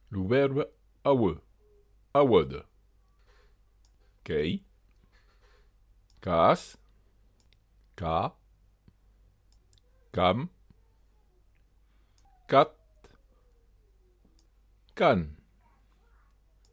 La lettre tonique [Repérée, ici en API, par une apostrophe " bi'nusi " précédent la syllabe tonique]